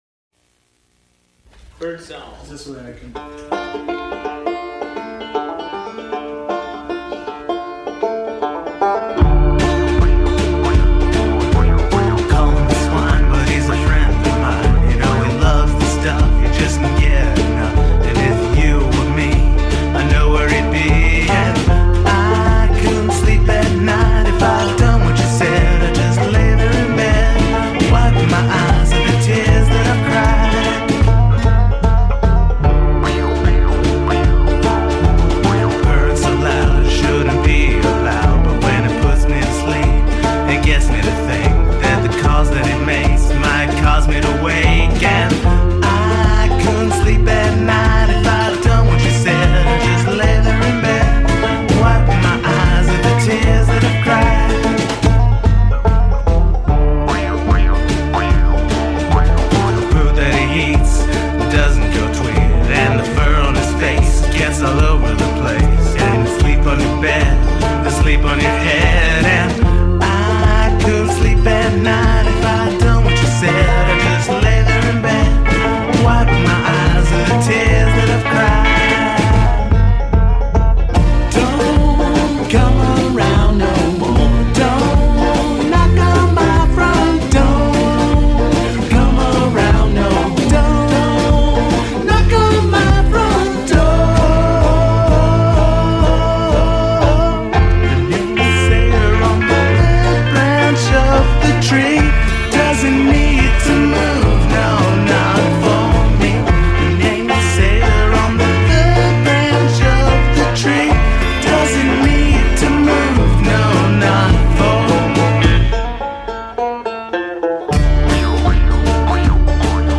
May I introduce The Flagpoles latest hit
Banjo